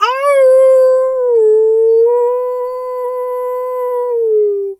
wolf_2_howl_long_01.wav